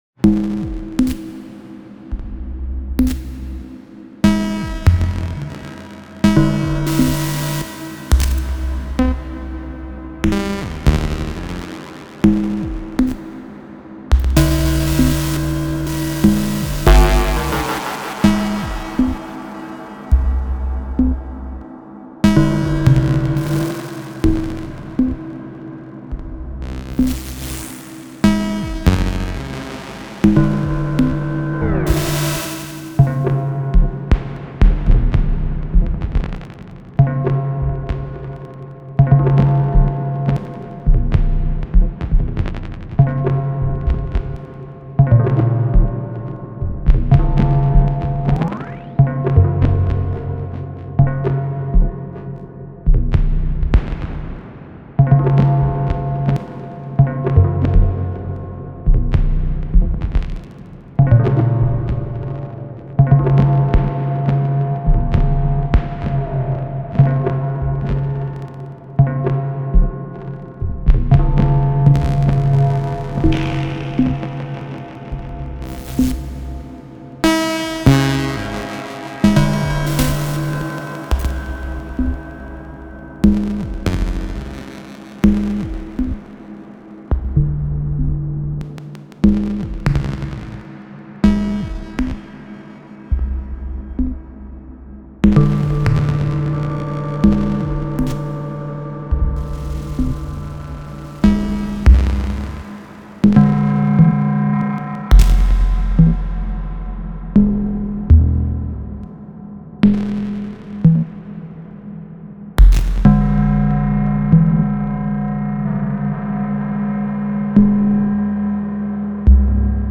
More nothing with Digitone.
At the moment I have difficulties falling in love with my Digitone, but I really adore these clicky sounds you have there!